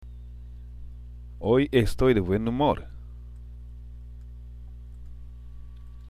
＜発音と日本語＞
（オイ　エストイ　デ　ブエン　ウモール）